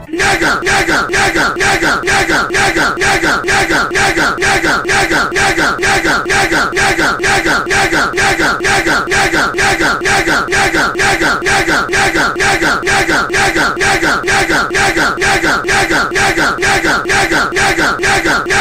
Fart Sound E Sound Effect Download: Instant Soundboard Button